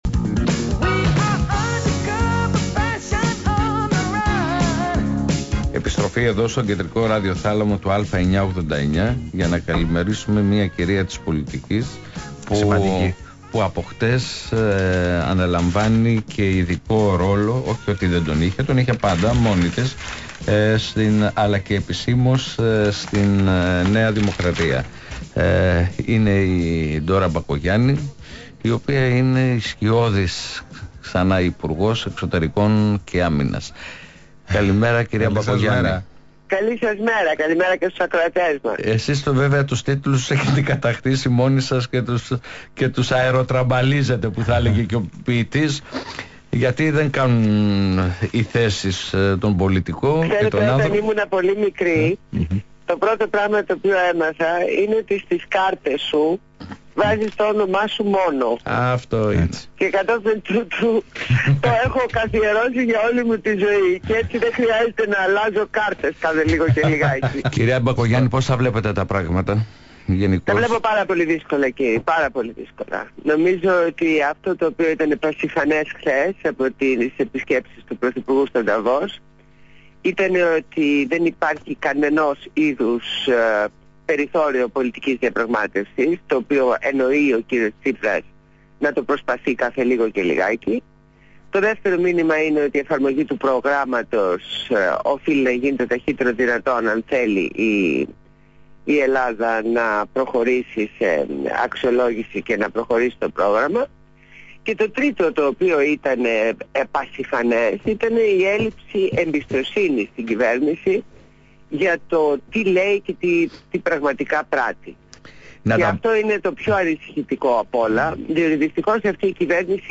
Συνέντευξη στο ραδιόφωνο του ALPHA 98,9